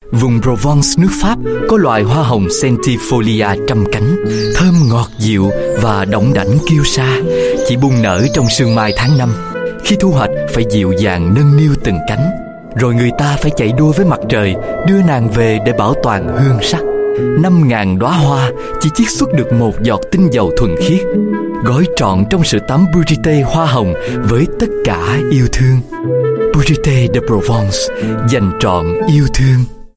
Viet South VIS